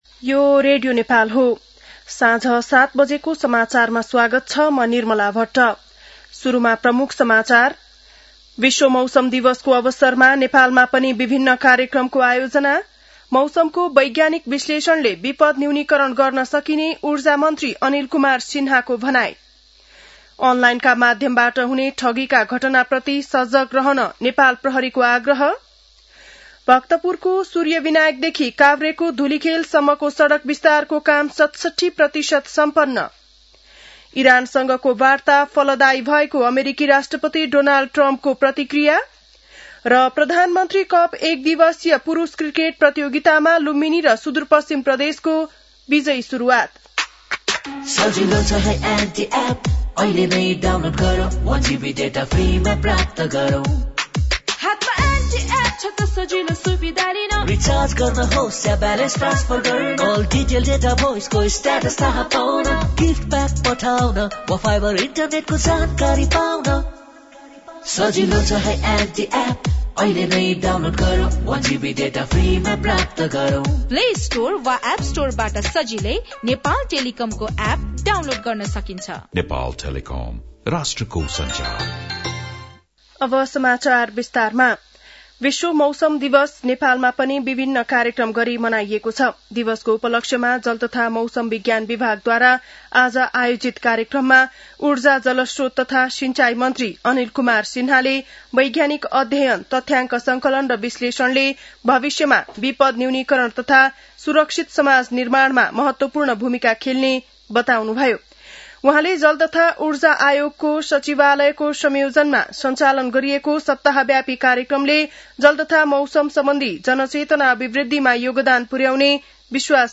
An online outlet of Nepal's national radio broadcaster
बेलुकी ७ बजेको नेपाली समाचार : ९ चैत , २०८२